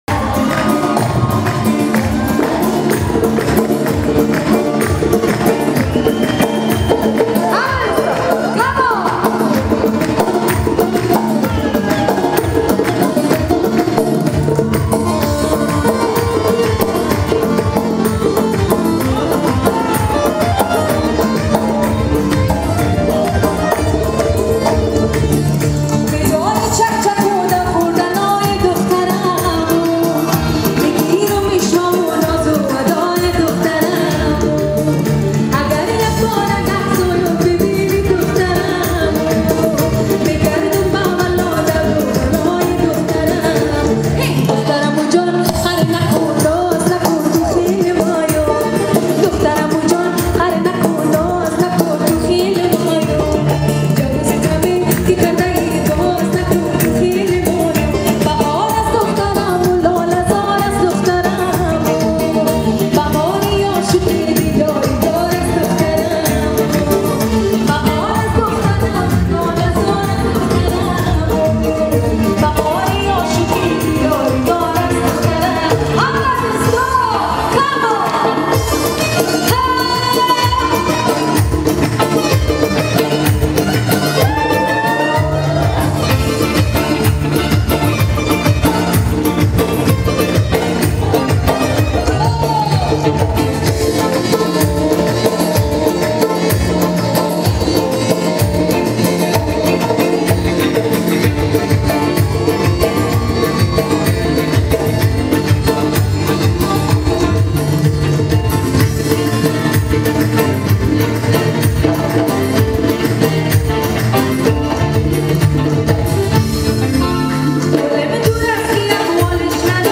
ریمیکس اهنگ افغانی
با صدای زن